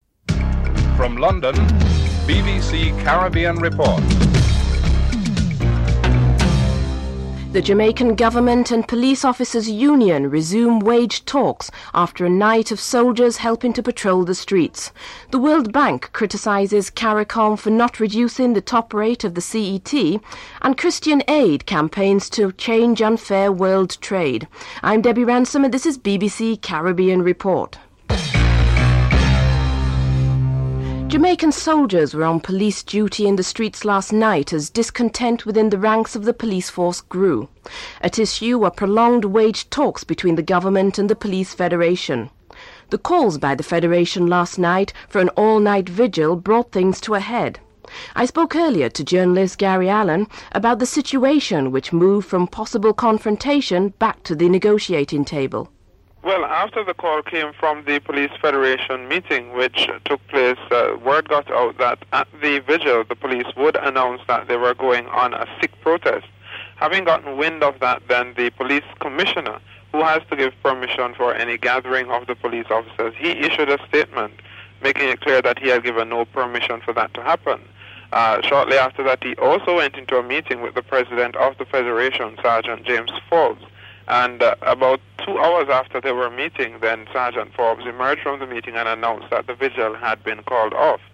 Report ends Prematurely.